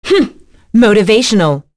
Priscilla-Vox_Skill2.wav